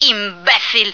flak_m/sounds/female1/est/F1sucker.ogg at 9e43bf8b8b72e4d1bdb10b178f911b1f5fce2398